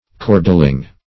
cordeling - definition of cordeling - synonyms, pronunciation, spelling from Free Dictionary
Search Result for " cordeling" : The Collaborative International Dictionary of English v.0.48: Cordeling \Cor"del*ing\ (k[^o]r"d[e^]*[i^]ng), a. [F. cordeler to twist, fr. OF. cordel.